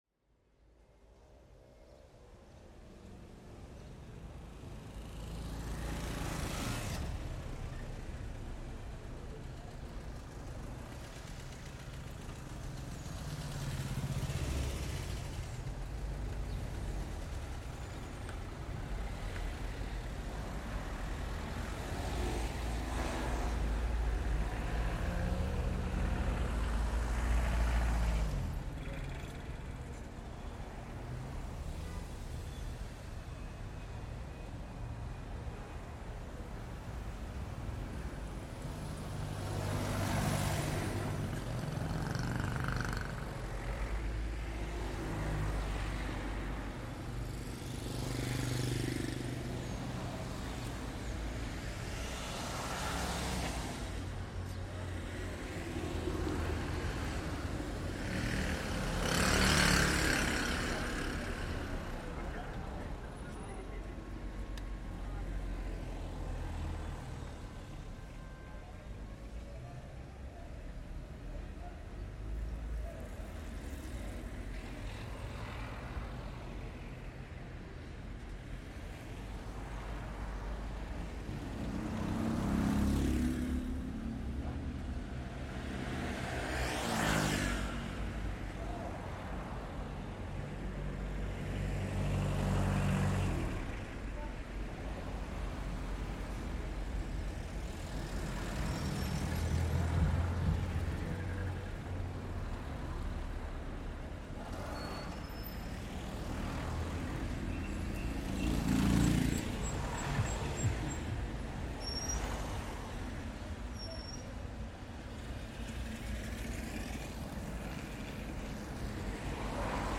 In the streets of Awrir, Morocco, you'll hear the constant hum of cars and trucks passing by, mixed with the sounds of people walking around. It's a lively scene that captures the everyday hustle and bustle of this vibrant Moroccan town.